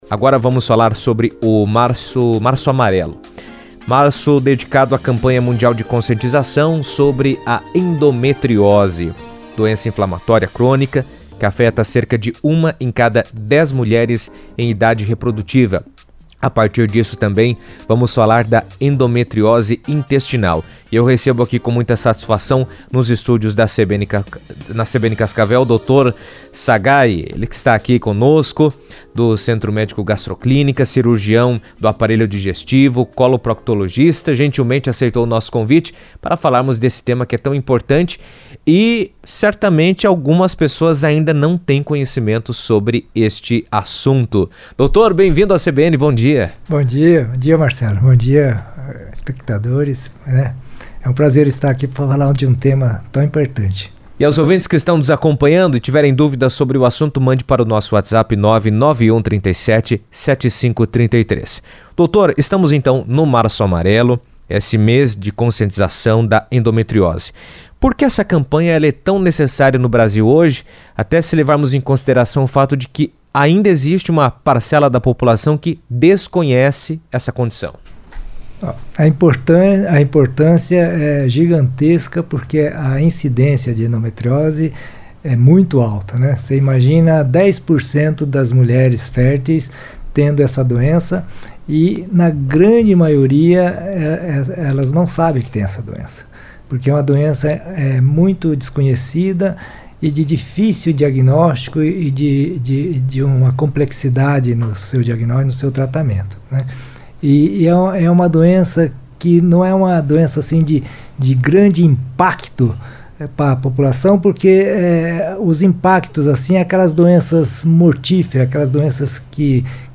A endometriose intestinal é uma forma da doença em que tecido semelhante ao que reveste o interior do útero cresce na parede do intestino, podendo causar dor abdominal intensa, alterações do hábito intestinal (como constipação, diarreia ou dificuldade para evacuar), sangramento retal e sintomas que muitas vezes se confundem com outras condições gastrointestinais. Em entrevista na CBN